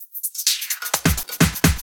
Fill 128 BPM (34).wav